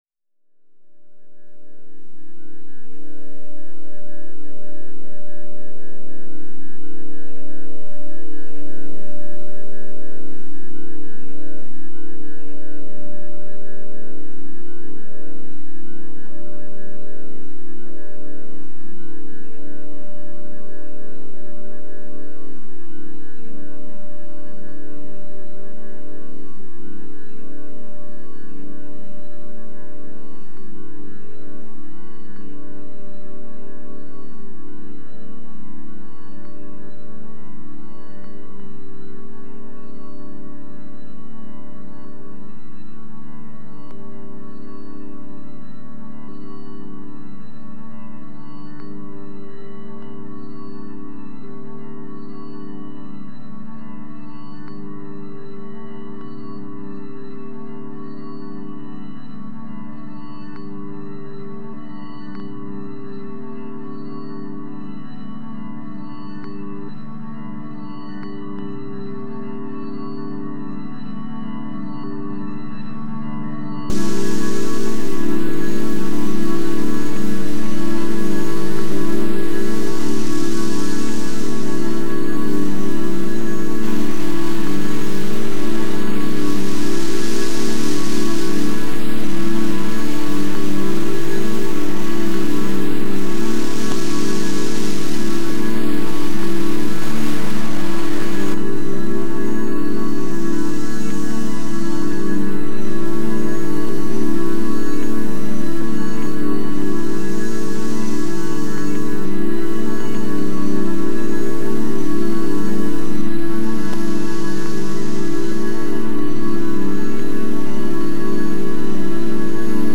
mp3 excerpt